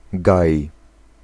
Ääntäminen
Synonyymit boqueteau bocage Ääntäminen France: IPA: [bɔs.kɛ] Tuntematon aksentti: IPA: [bɔsˈke] Haettu sana löytyi näillä lähdekielillä: ranska Käännös Ääninäyte 1. lasek {m} 2. gaj {m} 3. las {m} Suku: m .